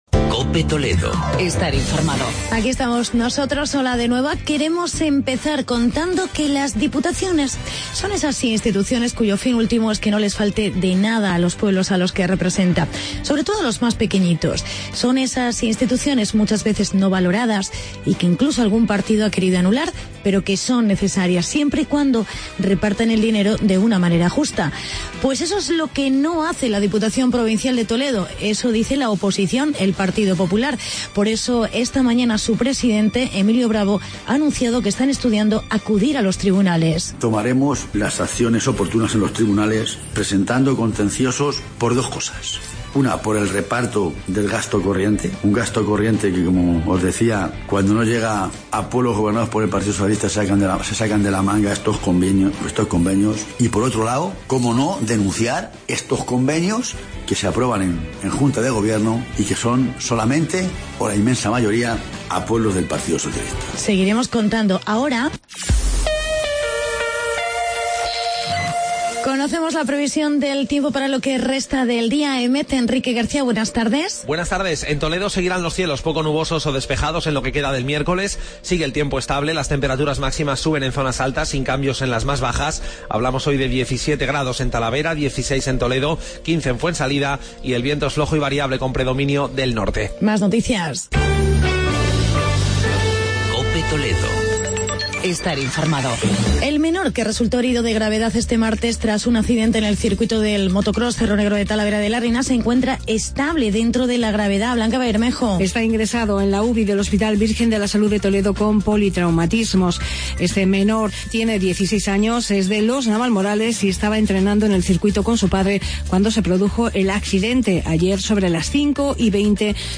Actualidad y entrevista con el alcalde de Talavera, Jaime Ramos.